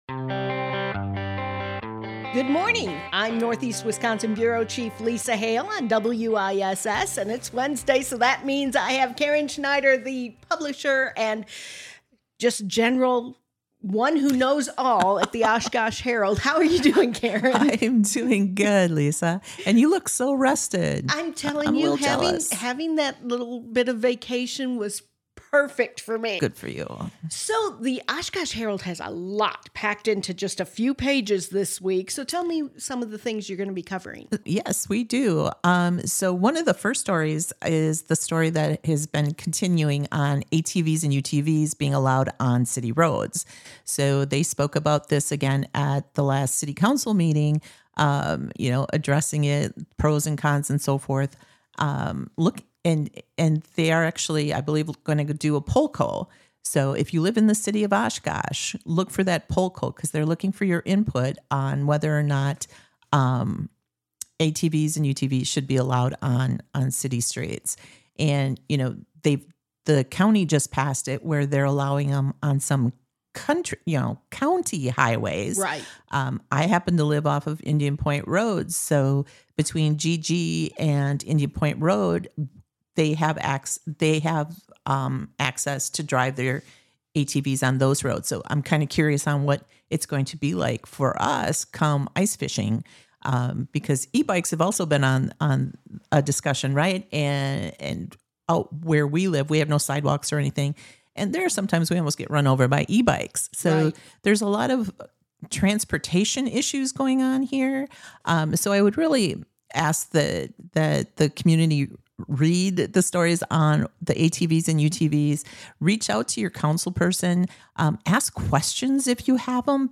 Conversations will range from entertainment to government to community involvement and more!